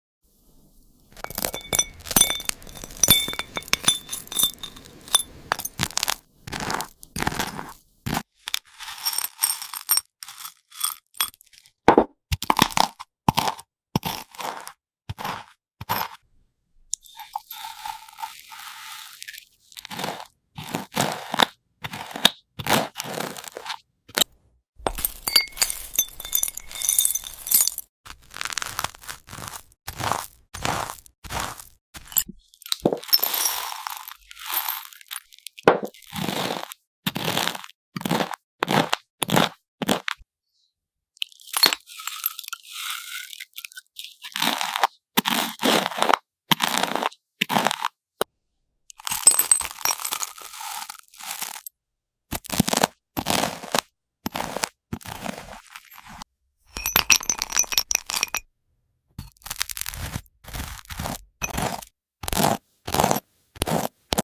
ASMR decompression and sleep aid! The rustling sound of glass leaves on toast is a must-have for stress relief and sleep!